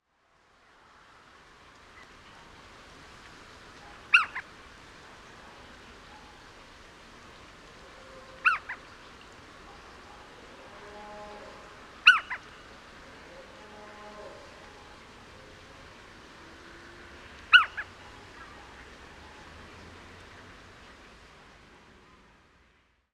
Steenuil
Athene noctua
steenuil.mp3